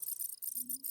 beeps.ogg